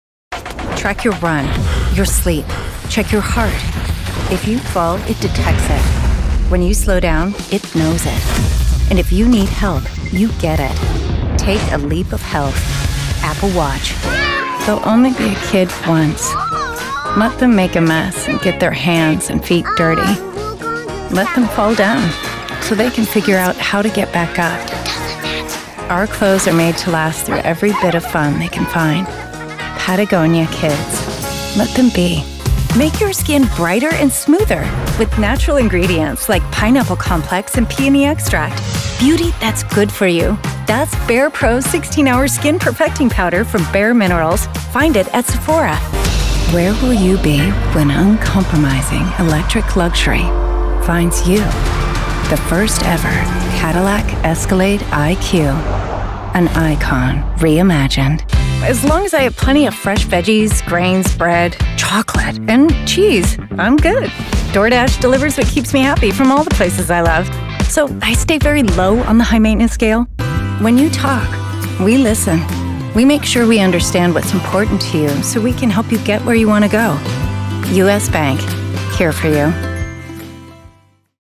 Fresh, friendly, smart and approachable female voice actor.
commercial demo ↓
Crisp and educated.